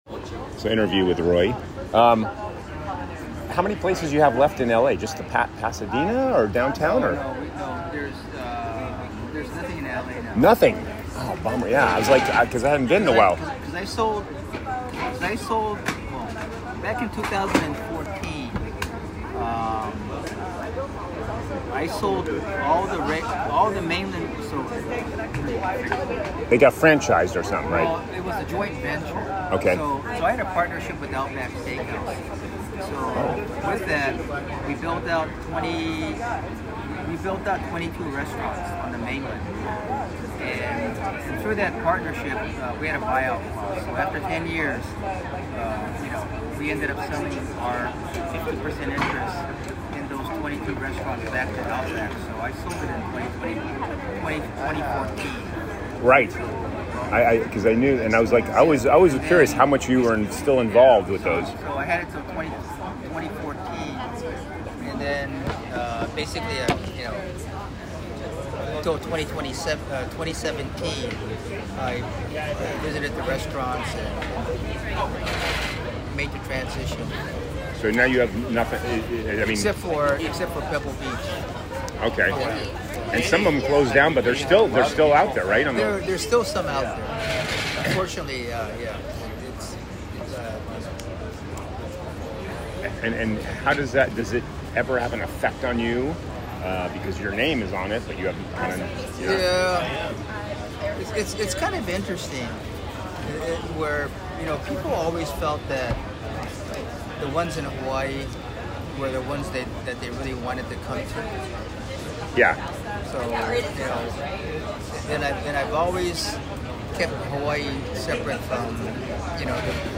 Audio Interview with Roy below about his restaurants and how he got into the Food and Wine Festival partnering with Alan Wong 2025: